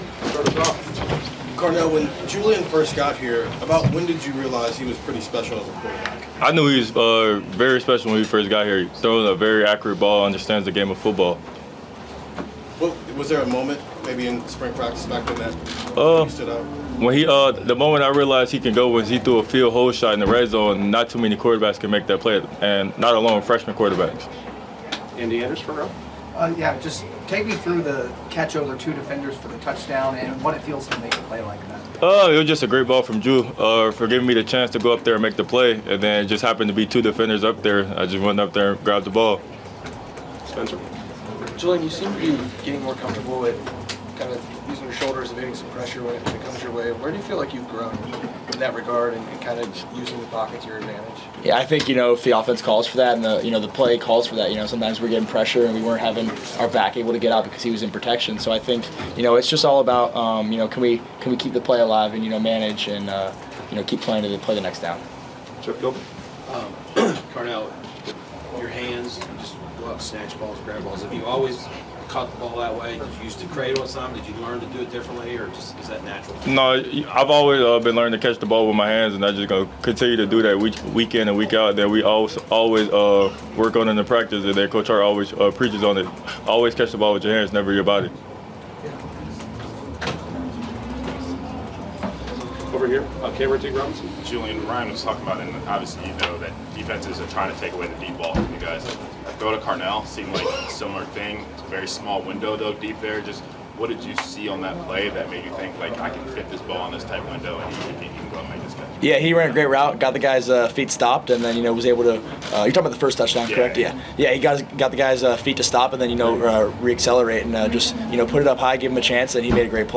Postgame Press Conference #1 OSU 34 Wisconsin 0